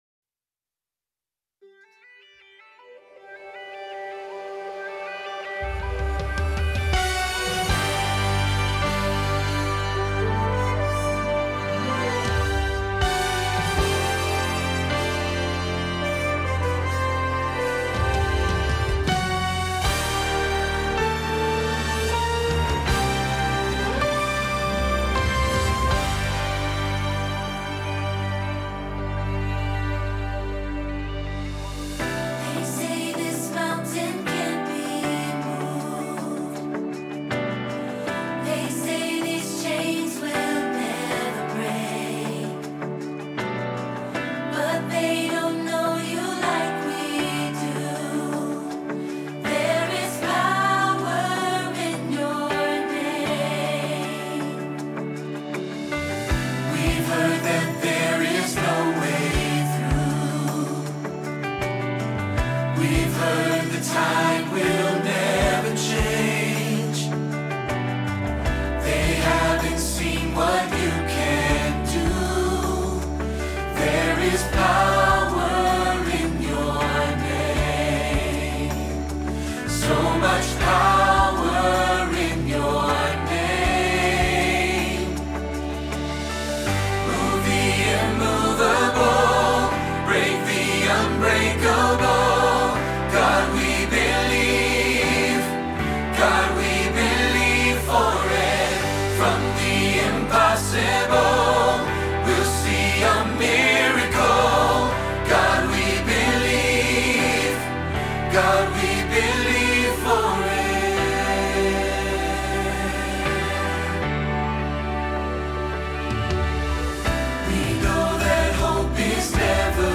Believe for It – Bass – Hilltop Choir